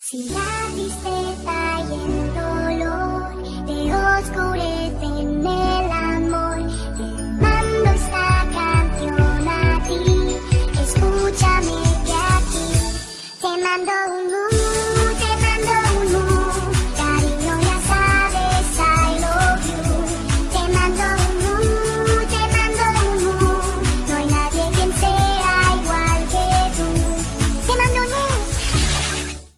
Category: Ringtone